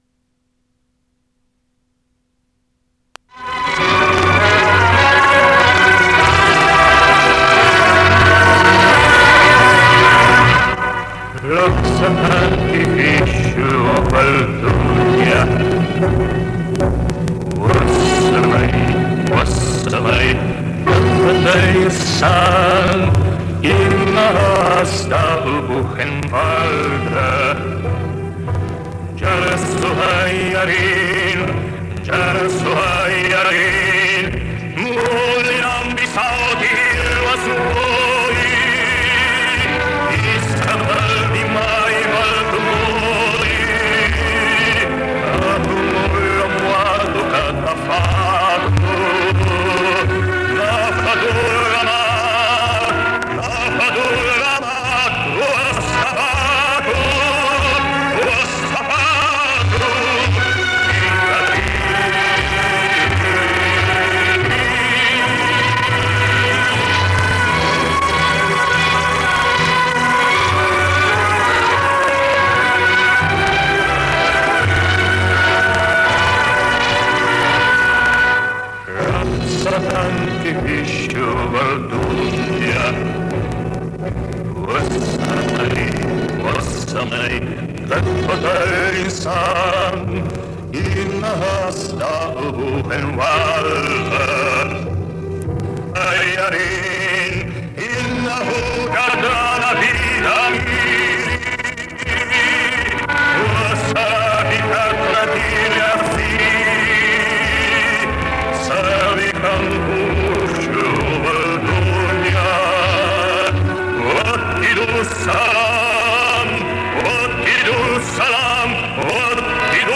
Очень отвратительная запись.
Я записала только арабский куплет.